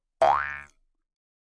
口腔竖琴第1卷 " 口腔竖琴13下形体短节奏
描述：口琴（通常被称为“犹太人的竖琴”）调到C＃。 用RØDENT2A录制。
Tag: 竖琴 调整Mouthharp 共振峰口 仪器 传统 jewsharp 共振峰 弗利